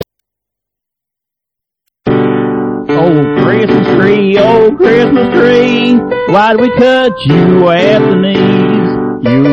baroque Christmas tunes